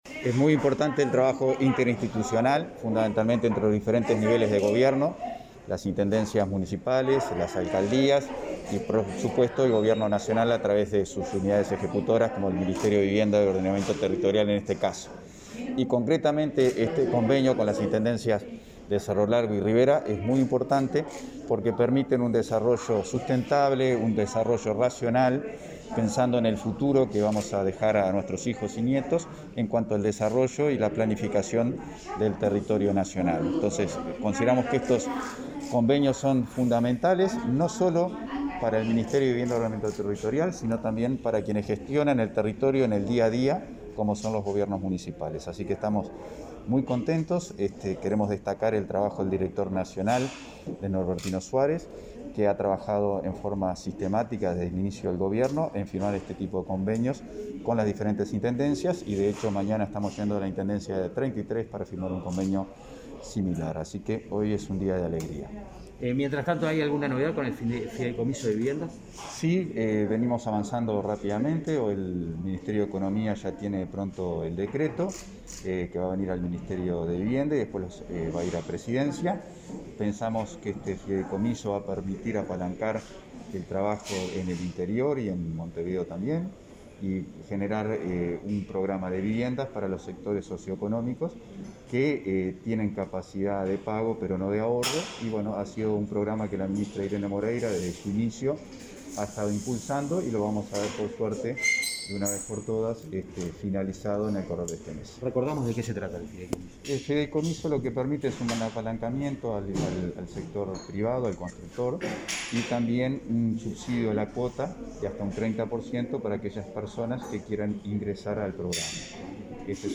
Declaraciones del subsecretario de Vivienda y Ordenamiento Territorial, Tabaré Hackenbruch